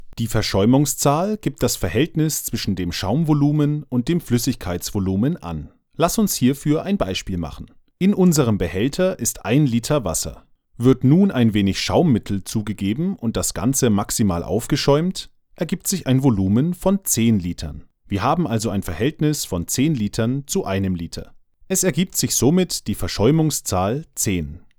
Deutscher Sprecher fĂŒr Vertonungen aller Art. Meine Stimme ist tiefgehend, ruhig, klar und atmosphĂ€risch.
Sprechprobe: eLearning (Muttersprache):
E-Learning_2.mp3